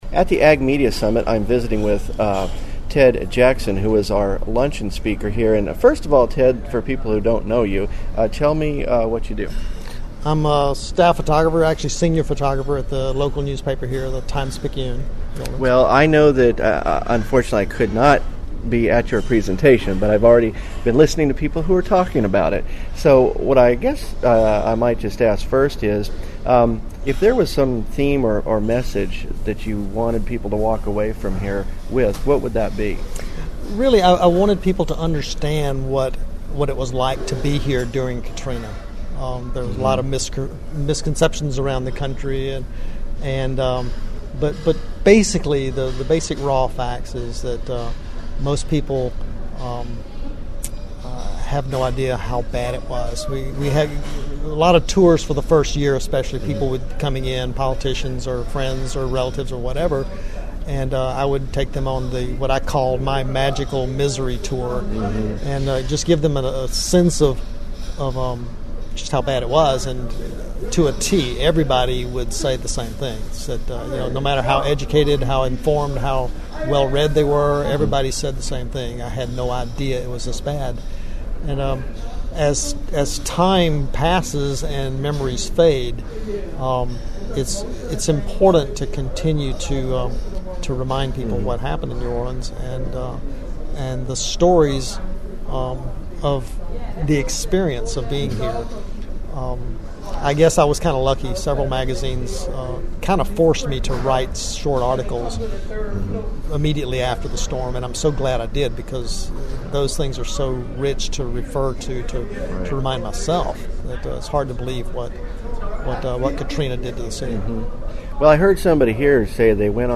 Interview
Ag Media Summit, Audio